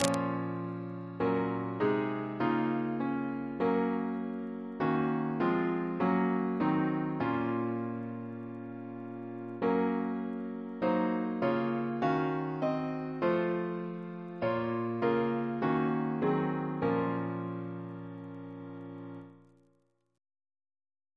Double chant in B♭ minor Composer: Herbert Howells (1892-1983) Reference psalters: ACP: 24; RSCM: 76